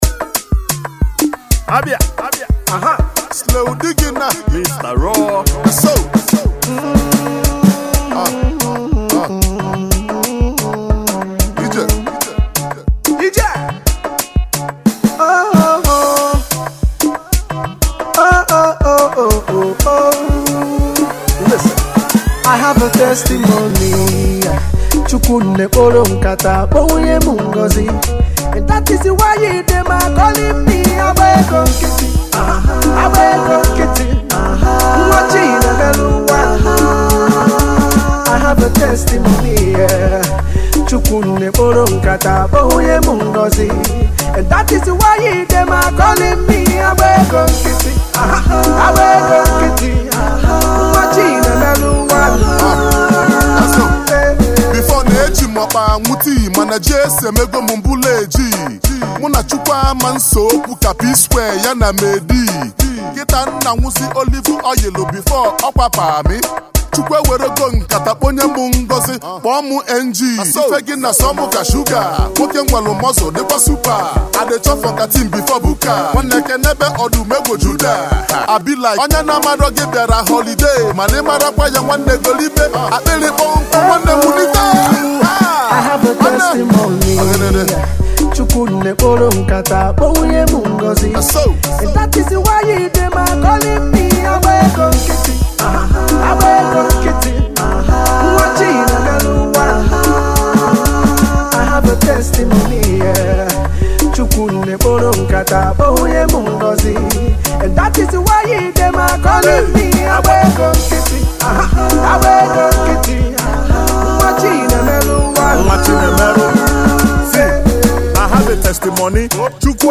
It’s a happy song that you would enjoy.